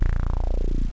00164_Sound_beep01b
1 channel